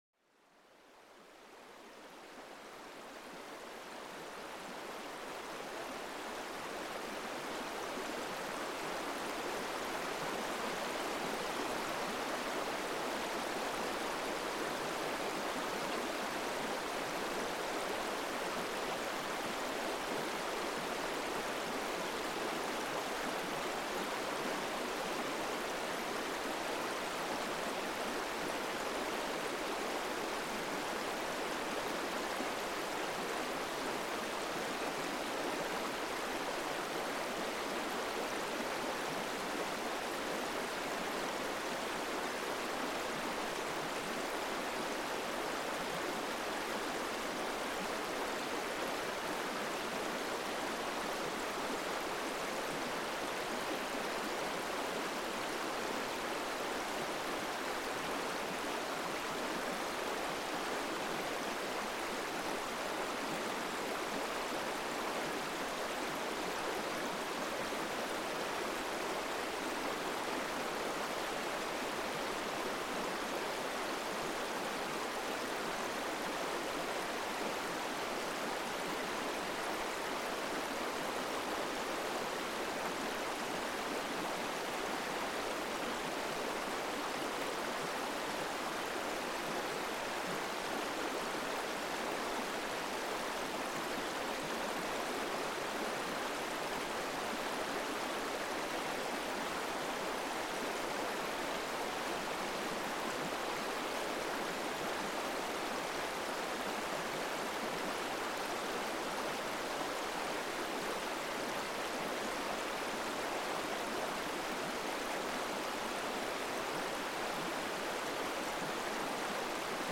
Plongez dans le murmure apaisant d'une rivière qui coule tranquillement, invitant à la détente et à l'évasion. Ce son naturel vous aide à vous libérer du stress quotidien et à retrouver une paix intérieure profonde.